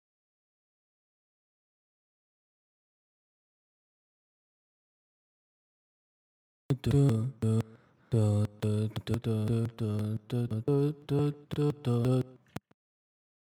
I use Focusrite Scarlett 18i6 interface / AT2035 Condenser / Logic Pro 9 for software. Only do Covers (Vocals Only maybe acoustic) First off, when i try to record vocals i get delays, hissing, and messy sound.
If i have the input monitoring he stays and its annoying hearing the hissy sound.